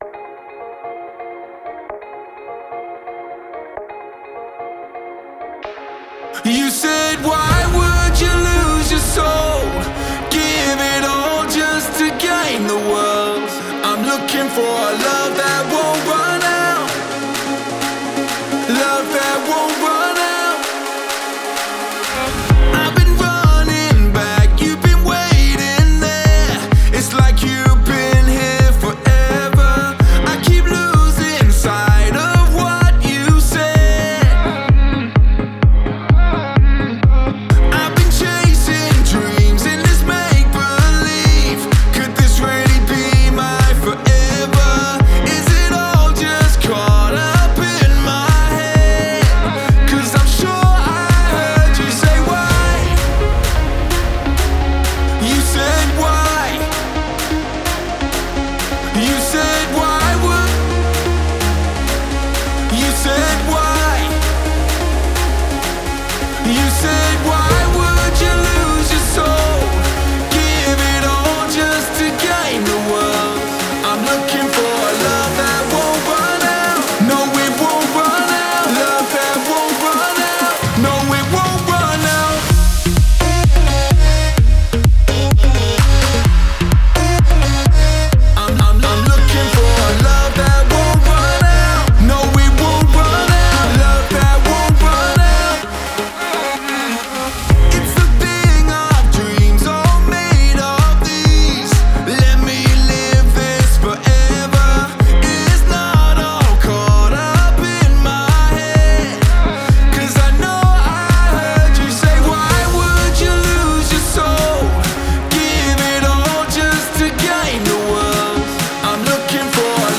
Exclusive Music Worship